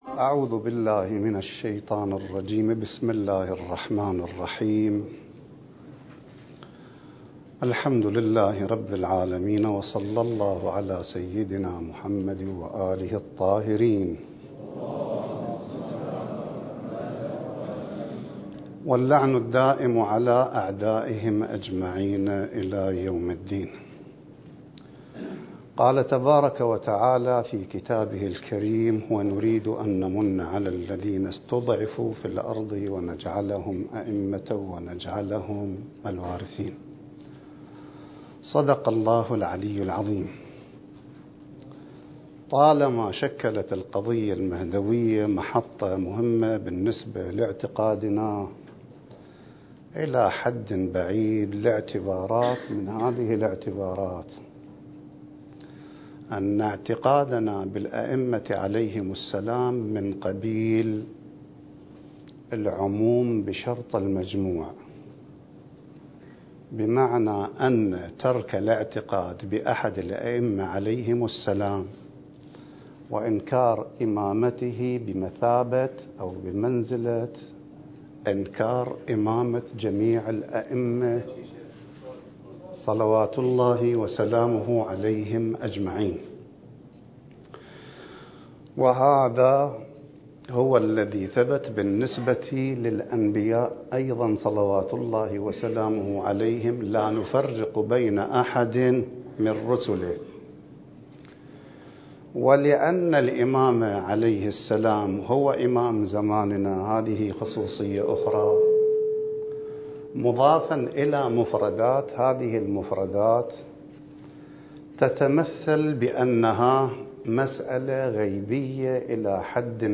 الدورة المهدوية الأولى المكثفة (المحاضرة الأولى)
المكان: النجف الأشرف